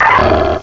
cry_not_lucario.aif